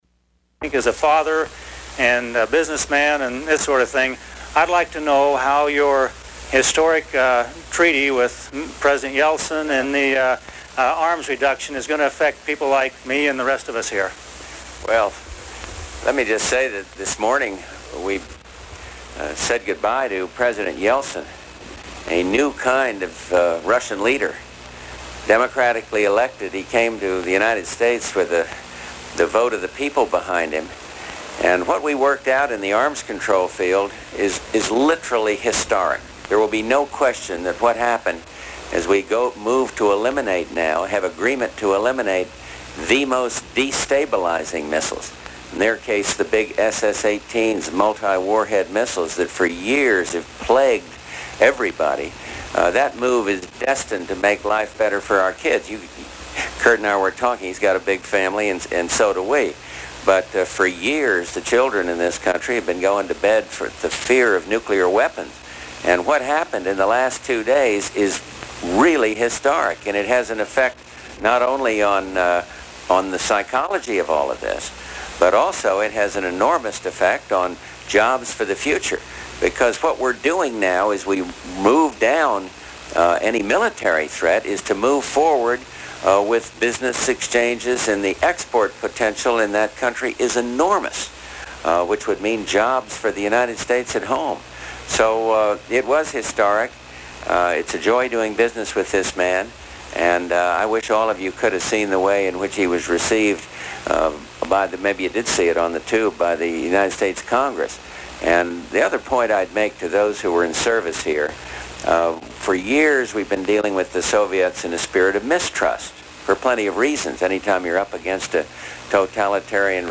George Bush answers questions from workers at the Evergreen plant in California